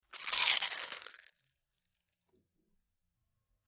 BiteManyPotatoChip PE390702
Bite Into Many Potato Chips, X7